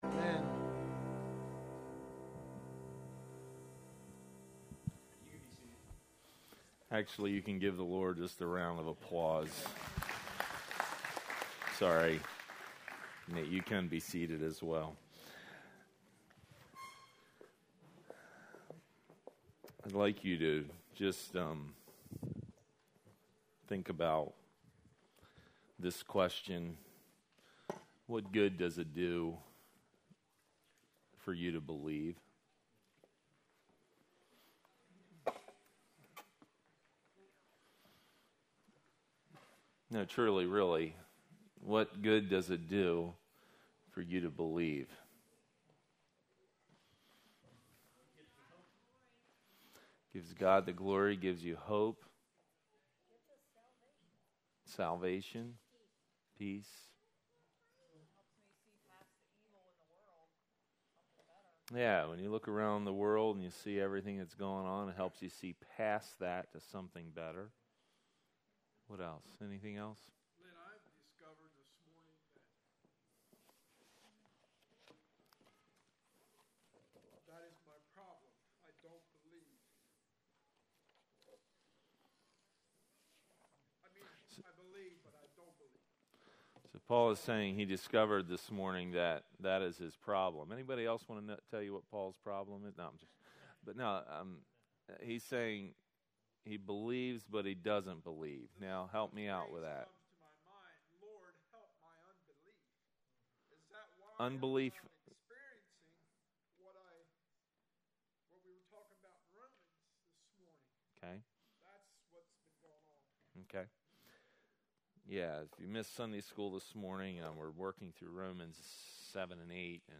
Maranatha Fellowship's Sunday Morning sermon recordings.
Sunday Sermons